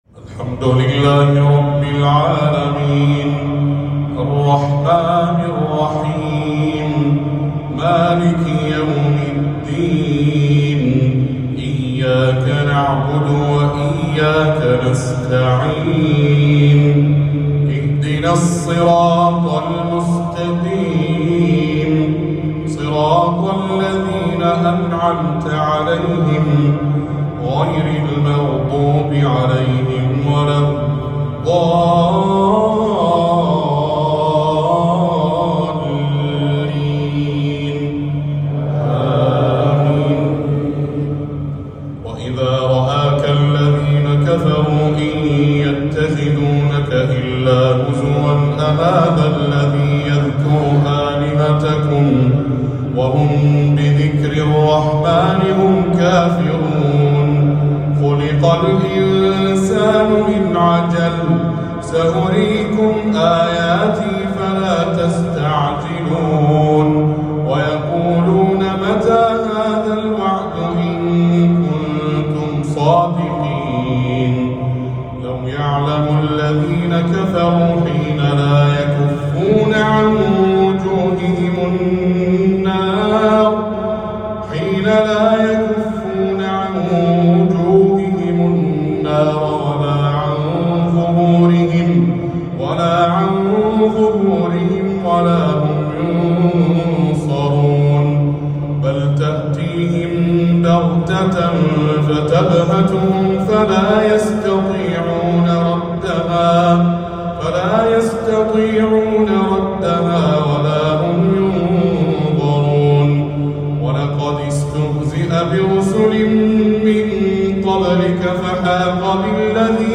تلاوة جميلة ومؤثرة لآيات عظيمة من سورة الأنبياء